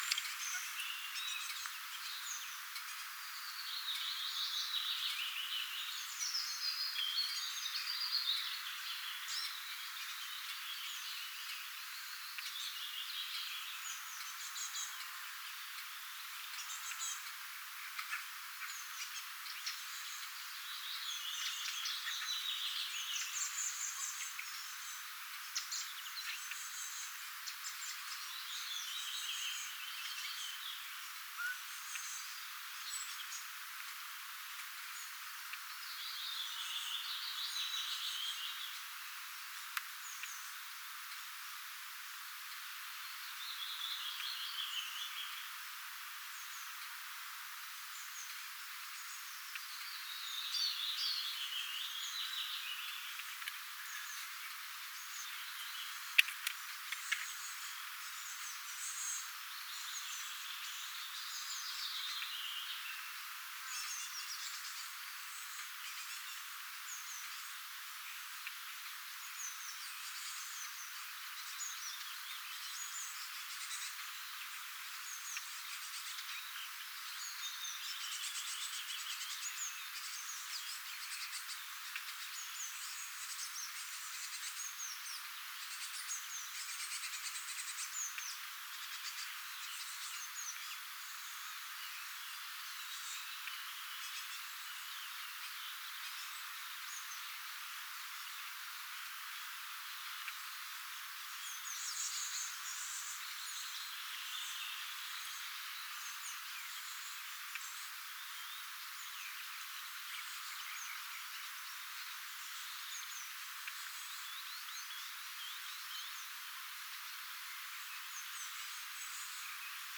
sinitiaispesueen ääntelyä
sinitiaispesue_jo_itsenaistynyt_iso_poikanen_nakyi.mp3